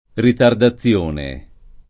ritardaZZL1ne] s. f. — forma lett. per ritardo, non com.: a Omero si rimproveravano le ritardazioni, le digressioni [a om$ro Si rimprover#vano le ritardaZZL1ni, le digreSSL1ni] (Croce); usata inoltre in qualche linguaggio tecnico